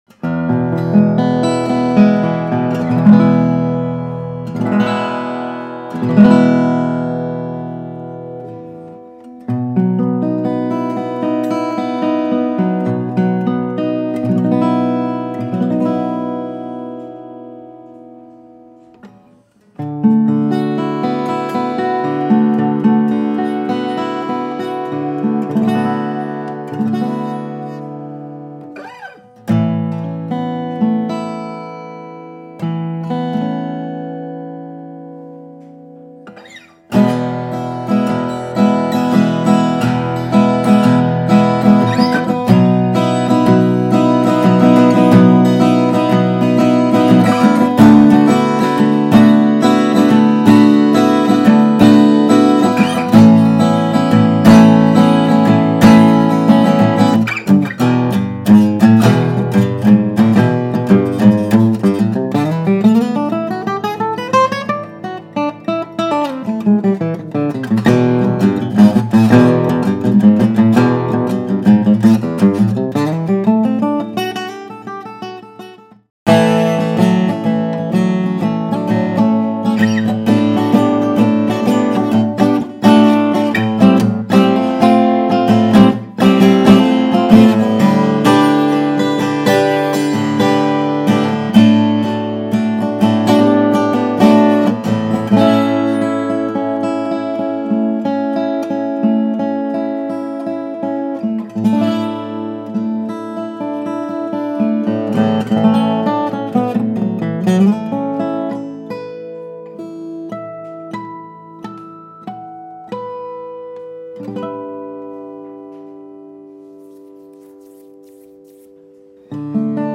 Martin CS 00-28 Marquis, built with premium Madagascar Rosewood, Adirondack Red Spruce and Hide Glue. Awesome tonemachine.